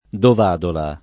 Dovadola [ dov # dola ]